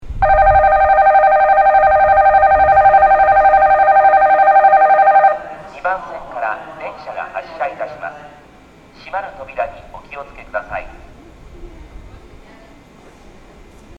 一部風が強い日に収録した音声があるので、風音が被っている音声があります。
発車ベル
5秒鳴動です。
発車放送は男性の放送です。
発車ベル・発車放送はPATLITEより流れます。